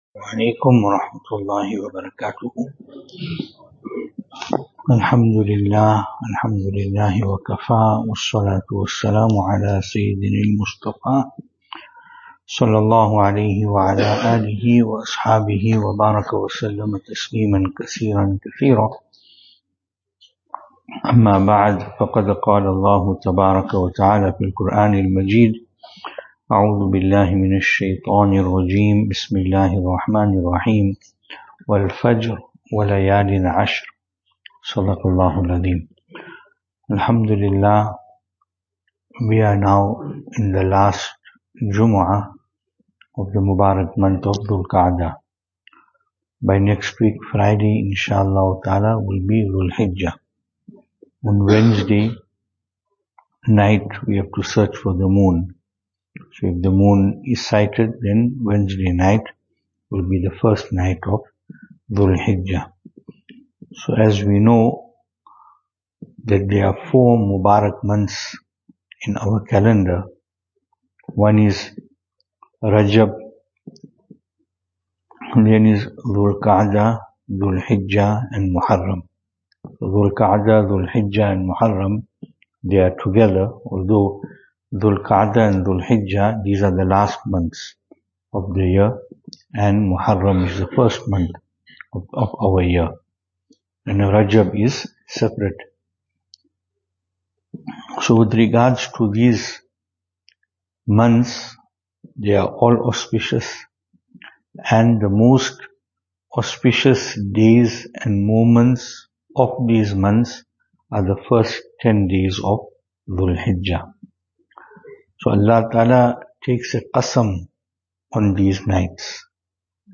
Jumu’ah Bayaan
2025-05-23 Jumu’ah Bayaan Venue: Albert Falls , Madressa Isha'atul Haq Series: JUMUAH Service Type: Jumu'ah .